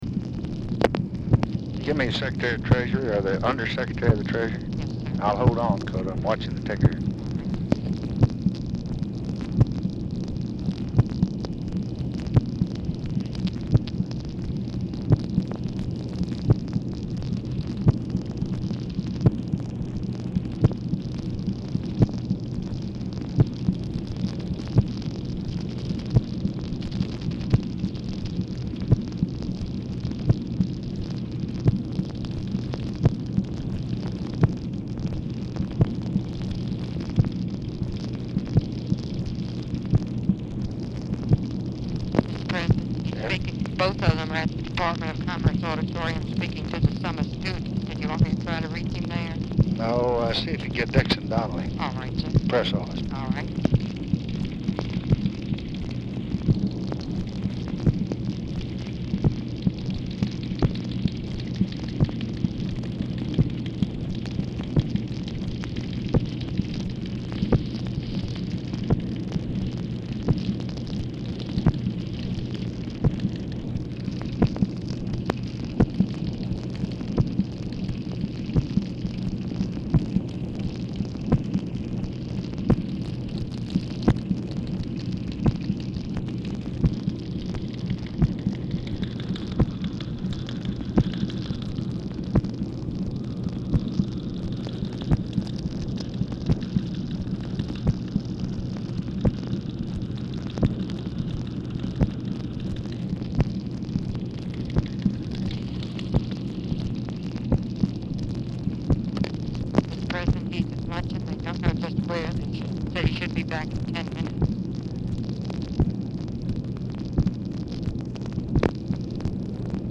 Telephone conversation
LBJ ON HOLD THROUGHOUT CALL; TICKER TAPE MACHINE IS AUDIBLE IN BACKGROUND
Format Dictation belt
OFFICE NOISE